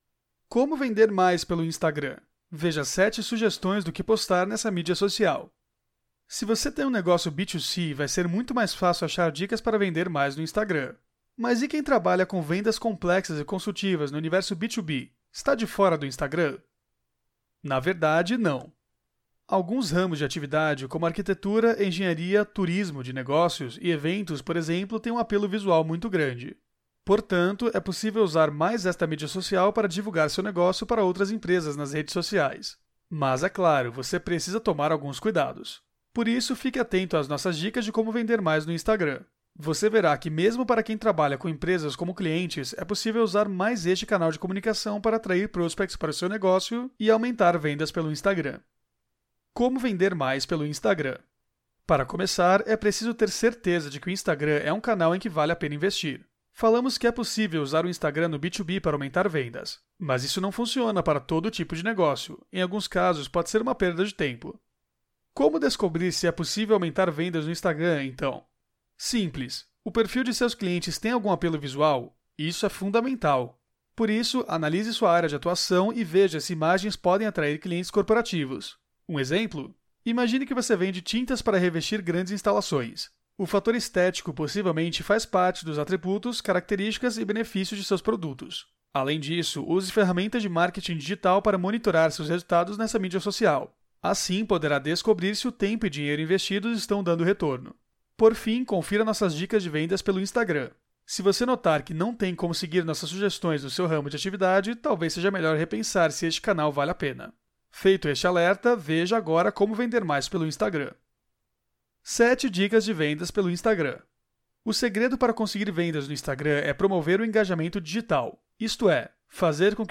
Aula 04
audio-aula-como-vender-mais-pelo-instagram-veja-7-sugestoes-do-que-postar-.mp3